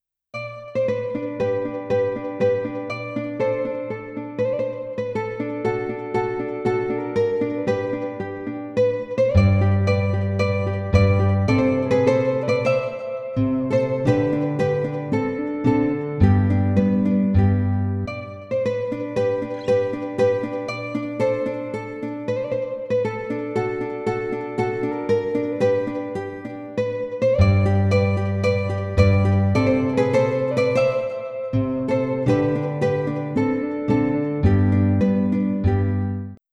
着信メロディ
尚、着メロの雰囲気を醸すために原曲のピッチを2度近く上げており、長さは30秒程度としています。